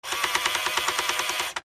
fo_toy_motor_03_hpx
Small toy motor spins at variable speeds. Motor, Toy Buzz, Motor